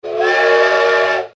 Download Train Whistle sound effect for free.
Train Whistle